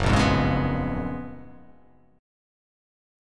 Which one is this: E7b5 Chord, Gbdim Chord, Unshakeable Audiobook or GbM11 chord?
GbM11 chord